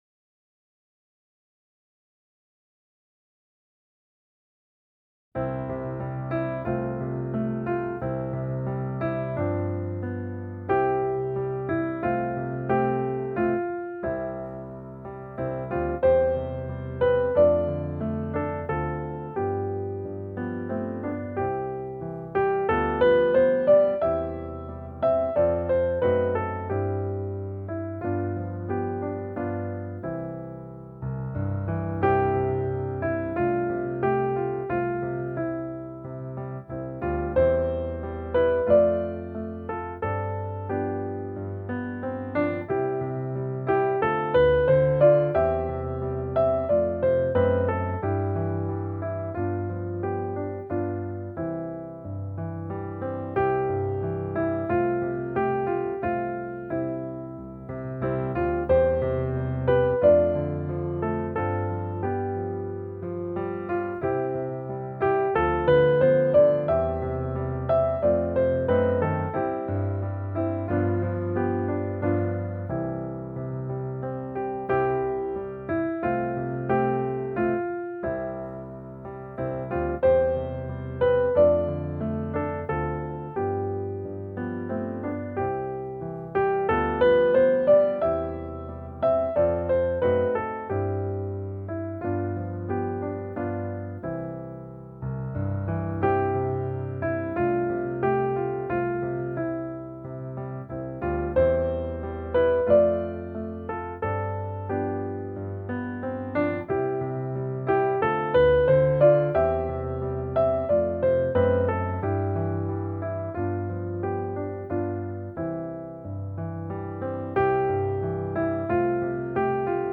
melodia ludowa
akompaniament